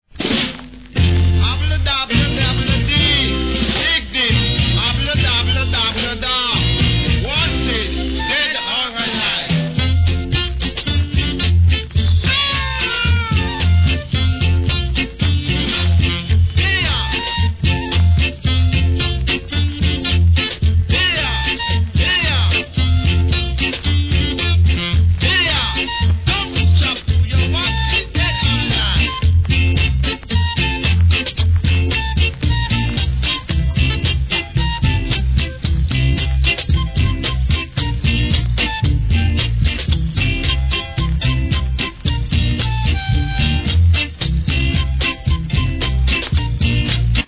7inch
REGGAE